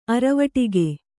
♪ aravaṭige